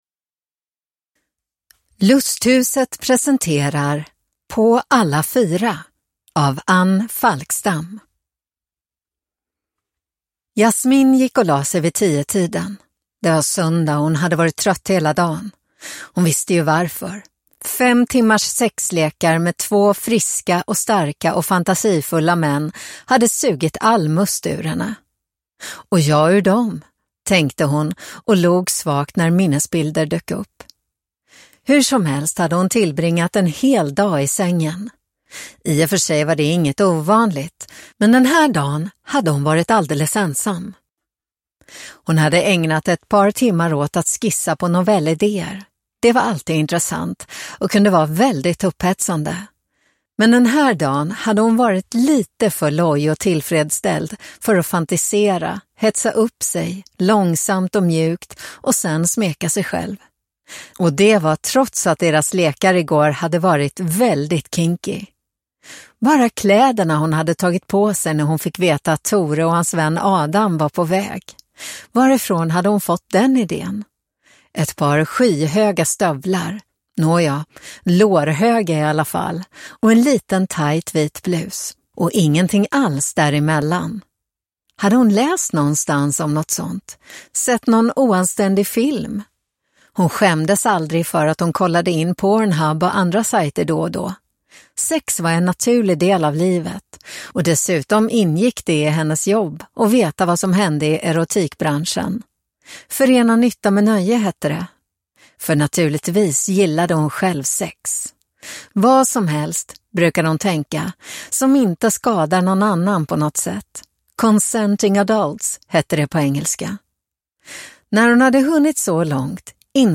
På alla fyra (ljudbok) av Anne Falkstam